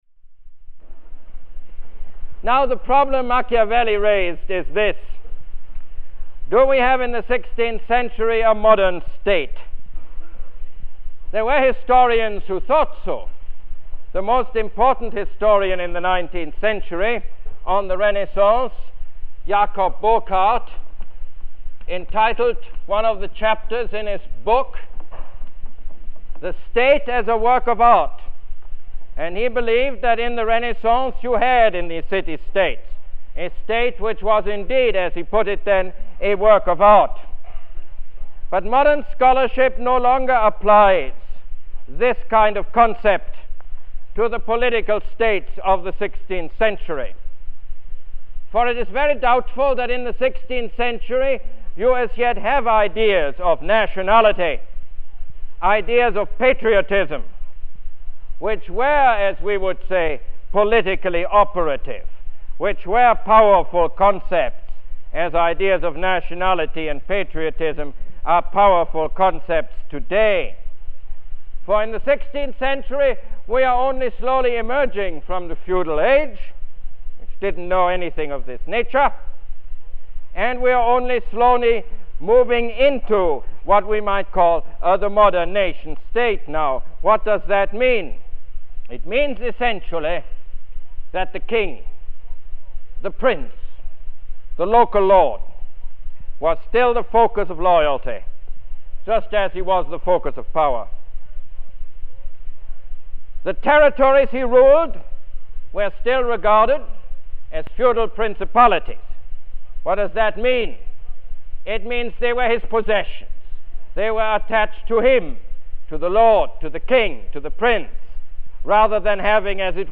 Lecture #8 - The Nation State